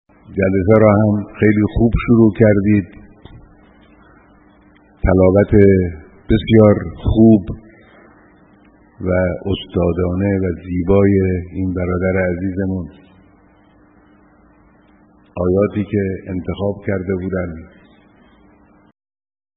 به گزارش خبرگزاری بین‌المللی قرآن(ایکنا)، در آستانه ۱۳ آبان روز ملی مبارزه با استکبار جهانی، هزاران نفر از دانش‌آموزان و دانشجویان، صبح روز گذشته 12 آبان با حضرت آیت‌الله العظمی خامنه‌ای رهبر انقلاب اسلامی دیدار کردند.
صحبت‌های مقام معظم رهبری درباره تلاوت